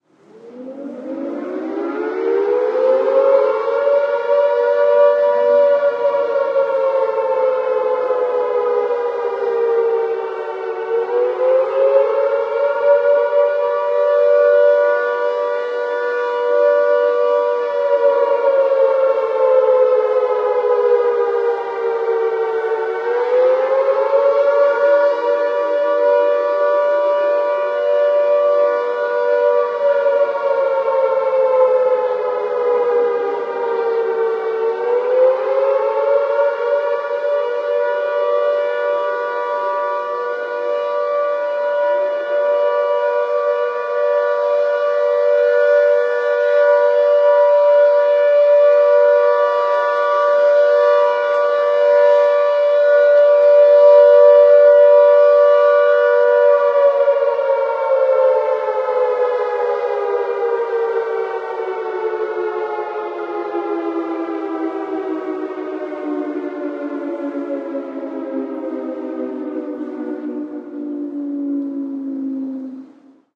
alarmAirraidSiren.ogg